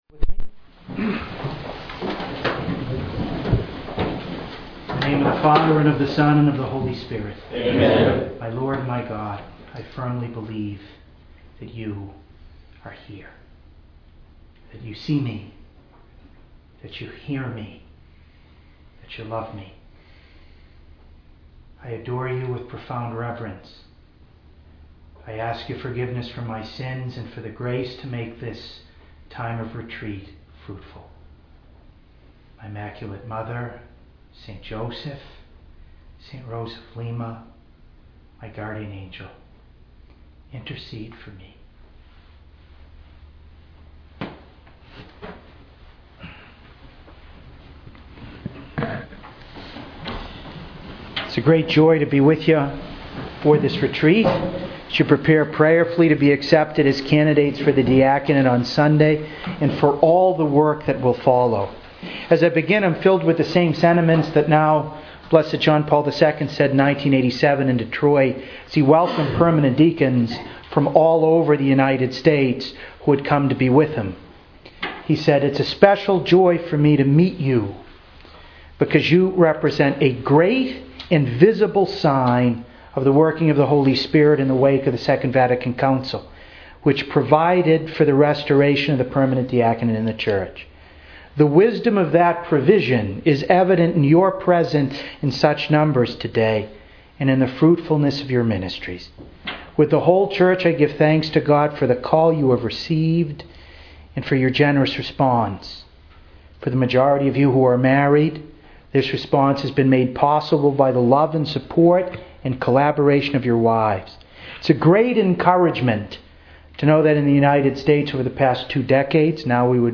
The Hunger to Grow in Faith, Retreat for Deacon Candidates, August 23-25, 2013 - Catholic Preaching
To listen to an audio of this conference, please click here: